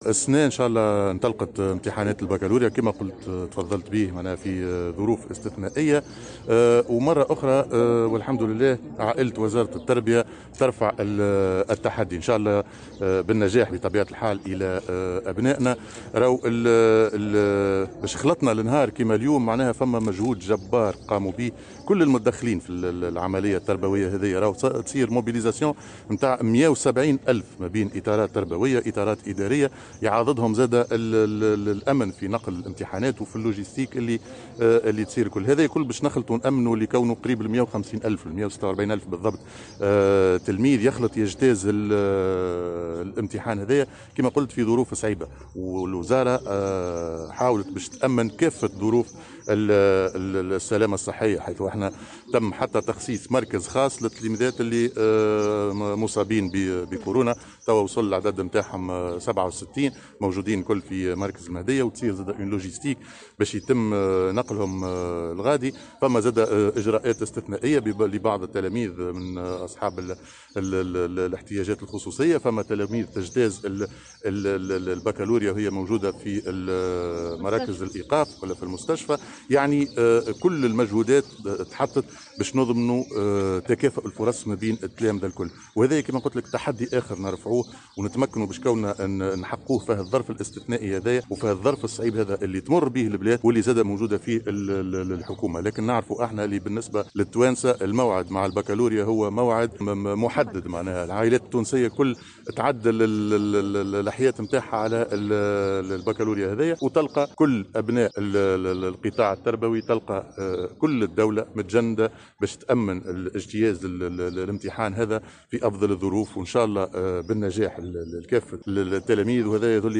وأضاف في تصريح للجوهرة أف أم، خلال زيارته اليوم، إلى المعهد الثانوي الفارابي بالمرناقية، أنّ دورة 2021، ستظل في ذاكرة التلاميذ، نظرا لخصوصية المرحلة التي تمر بها البلاد (جائحة كورونا).